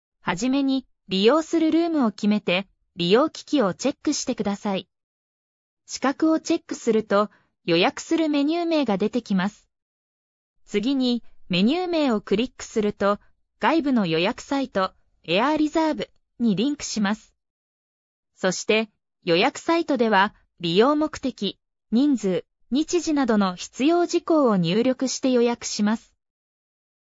予約方法の説明（AI音声で案内）